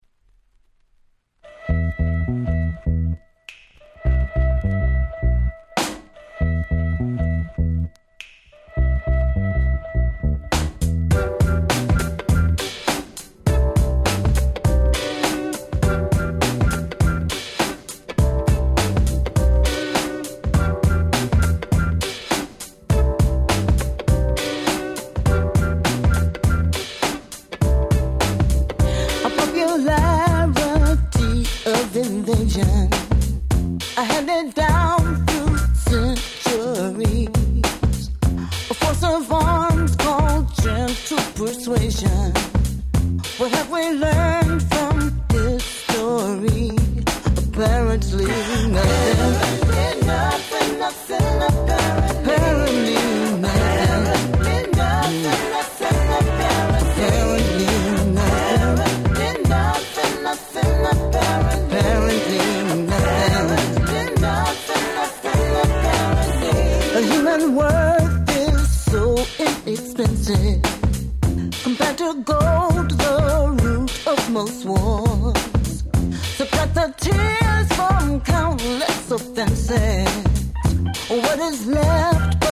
99' Nice Acid Jazz !!